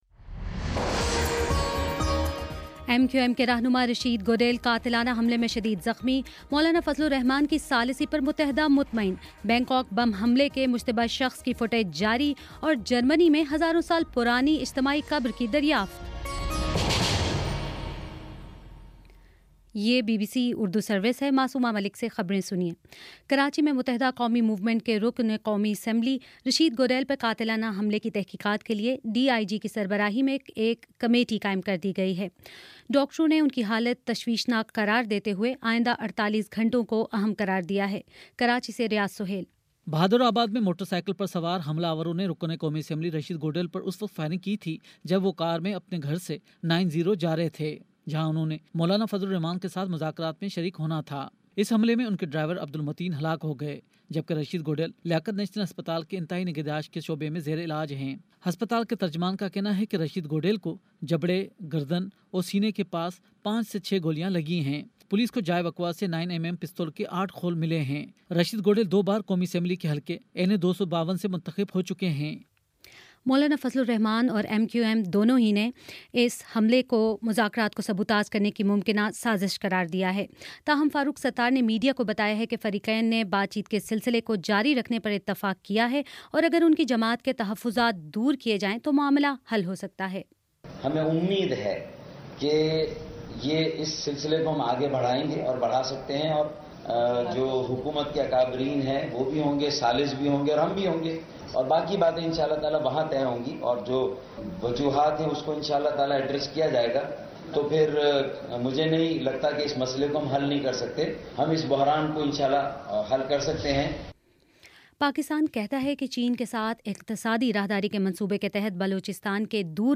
اگست 18: شام چھ بجے کا نیوز بُلیٹن